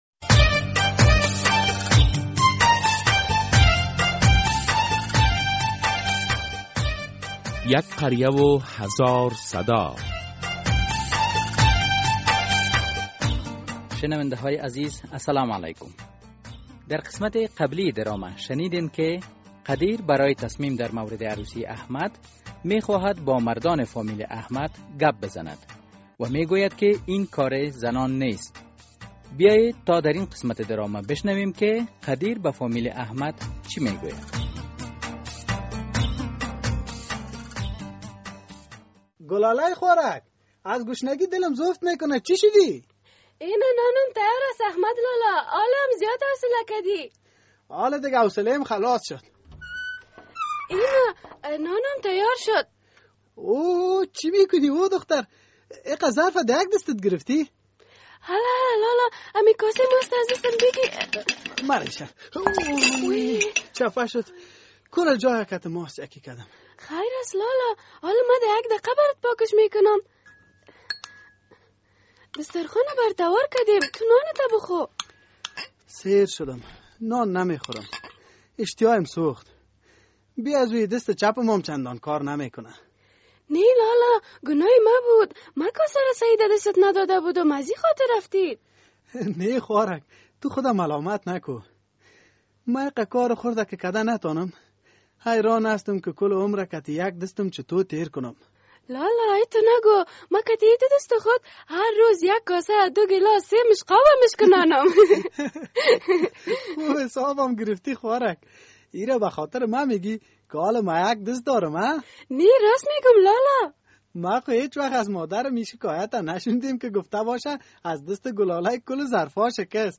در این درامه که موضوعات مختلف مدنی، دینی، اخلاقی، اجتماعی و حقوقی بیان می گردد هر هفته به روز های دوشنبه ساعت 3:30 عصر از رادیو آزادی نشر می گردد.